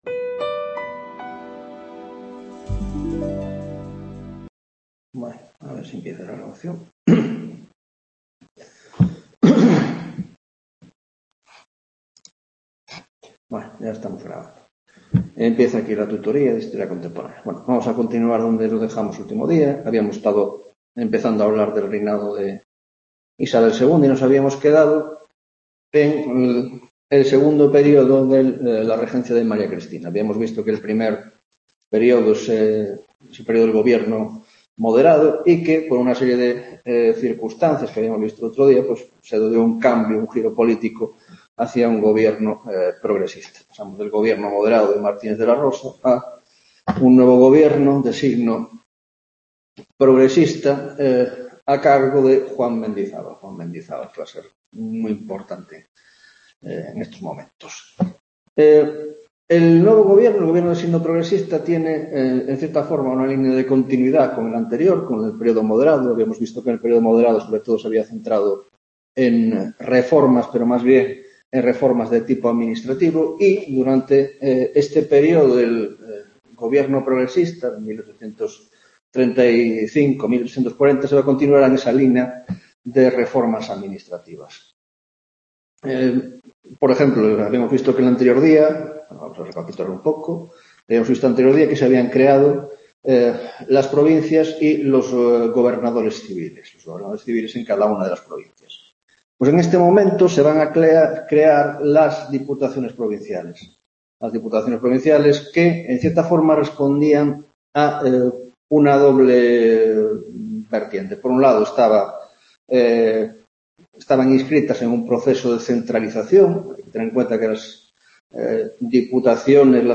10ª Tutoría de Historia Contemporánea - Reinado de Isabel II, 2ª parte